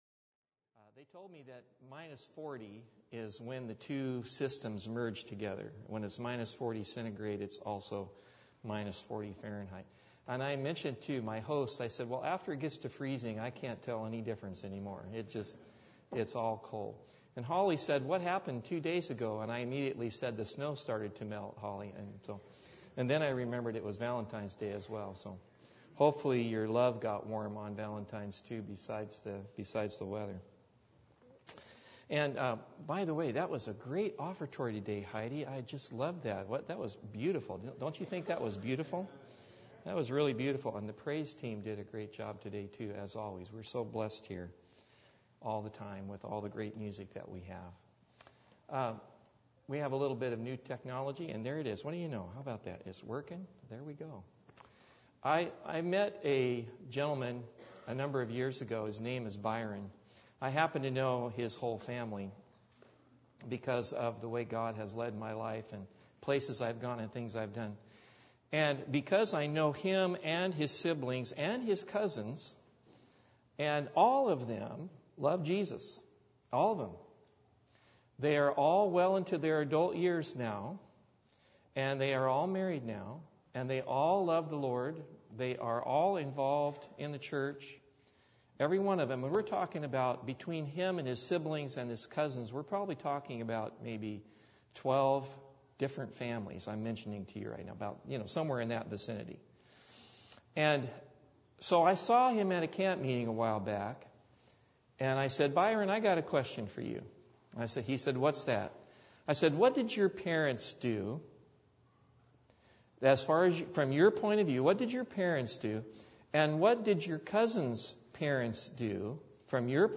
Service Type: Sabbath